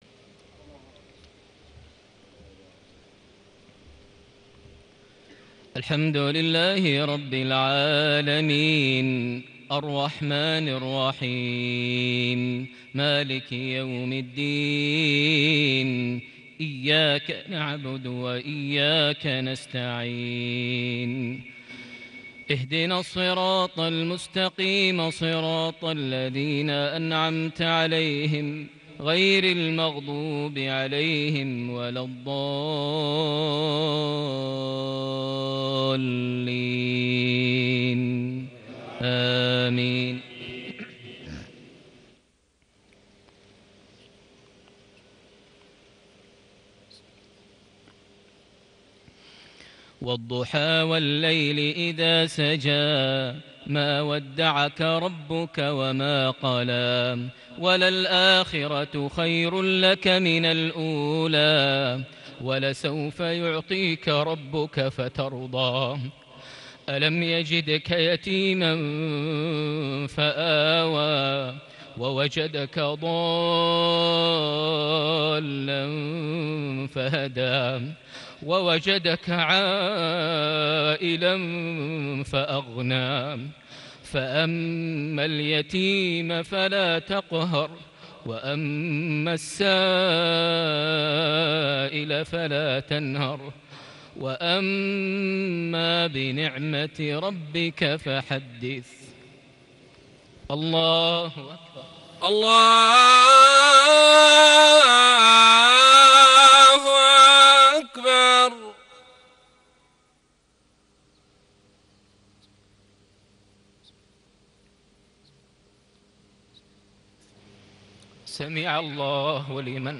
صلاة المغرب ٢٢ ربيع الآخر ١٤٣٨هـ سورتي الضحى / الشرح > 1438 هـ > الفروض - تلاوات ماهر المعيقلي